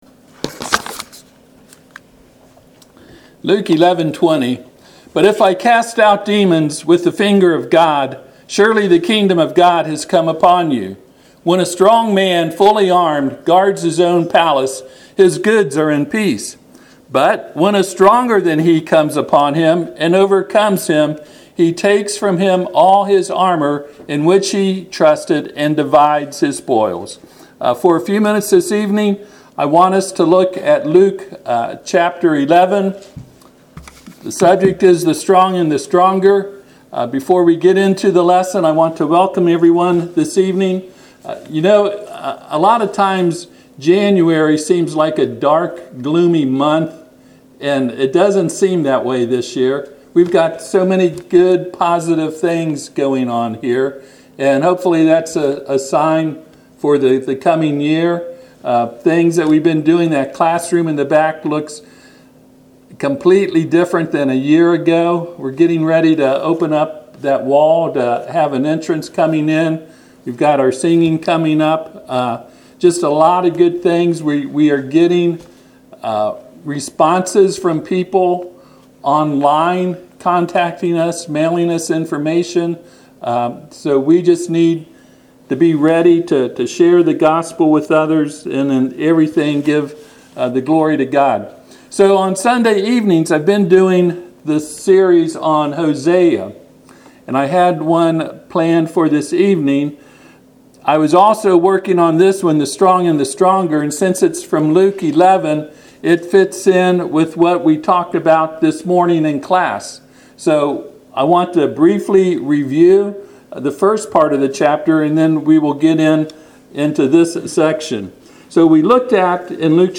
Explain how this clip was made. Passage: Luke 11:14-22 Service Type: Sunday PM